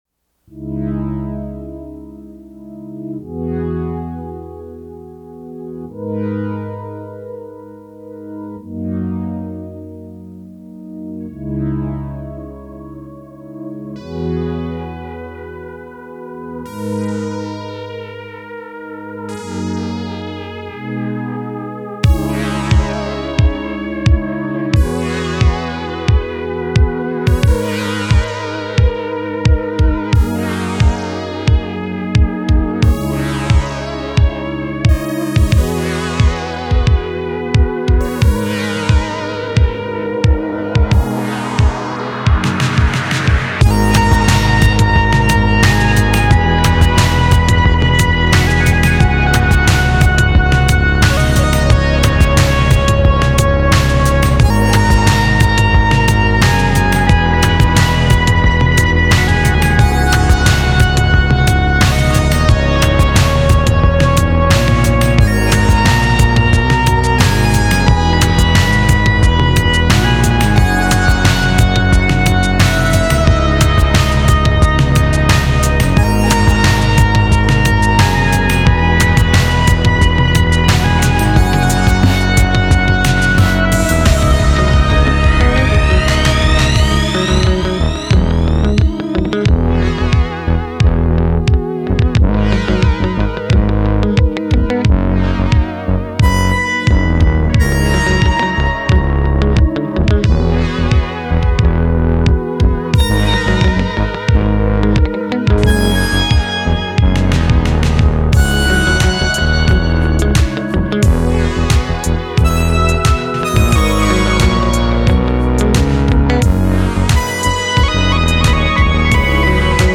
mode: sad
Genres:Dance and electronics